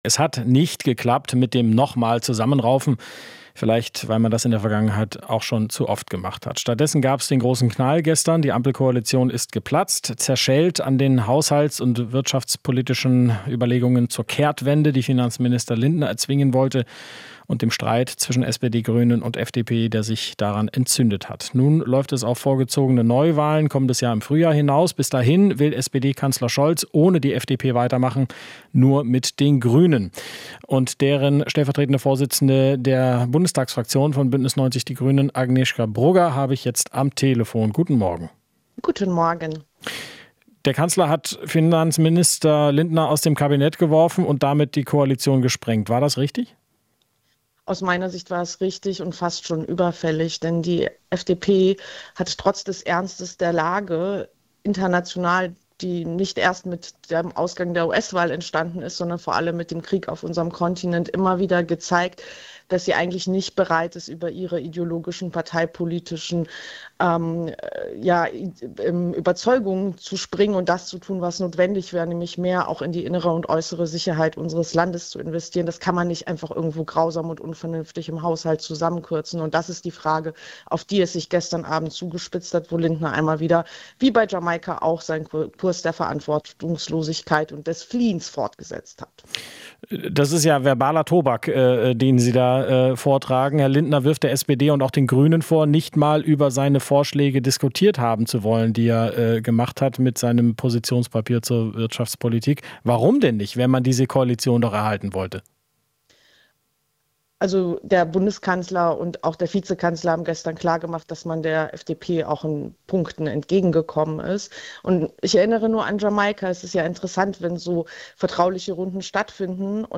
Interview - Brugger (Grüne): Koalitionsbruch "fast schon überfällig"